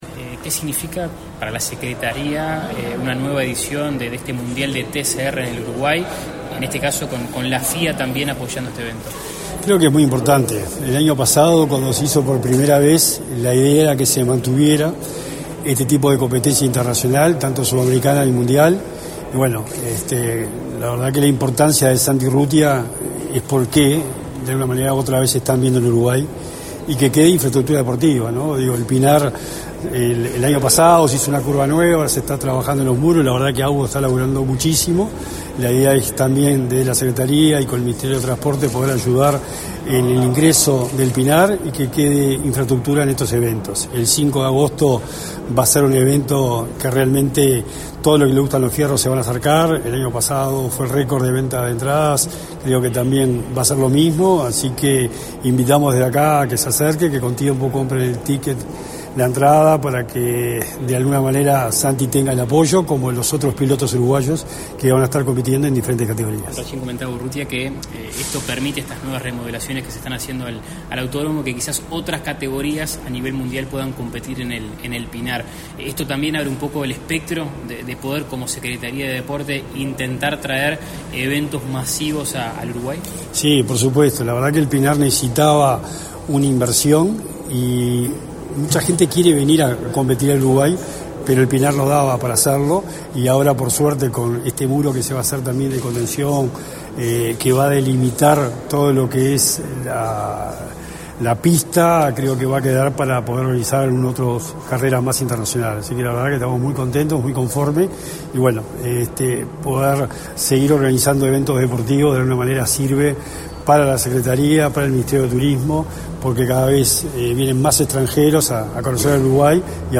Declaraciones a la prensa del secretario nacional del Deporte, Sebastian Bauzá
Declaraciones a la prensa del secretario nacional del Deporte, Sebastian Bauzá 03/07/2024 Compartir Facebook X Copiar enlace WhatsApp LinkedIn Tras la presentación de la competencia del TCR World Tour South América, este 3 de julio, el secretario nacional del Deporte, Sebastián Bauzá, realizó declaraciones a la prensa.
bauza prensa.mp3